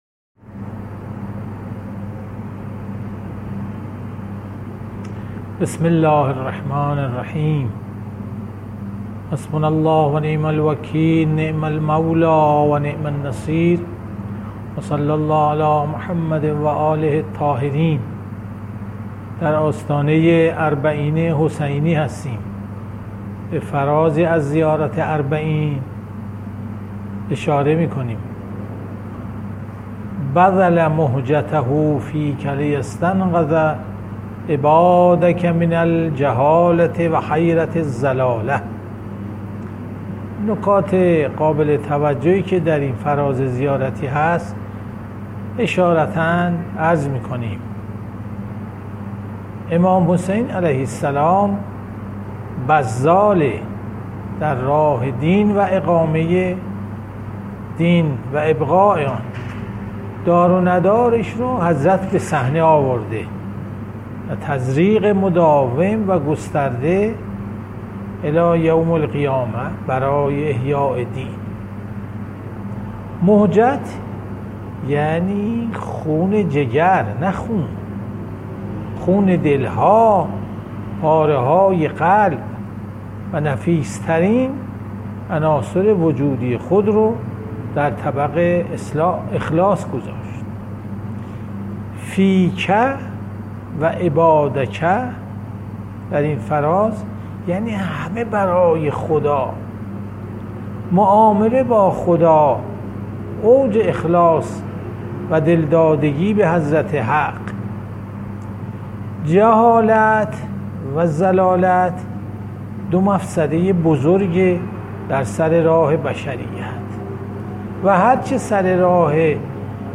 جلشه هفتگی تفسیر قرآن، سوره فجر، ۰۴ مهر ۱۴۰۰
جلسه مجازی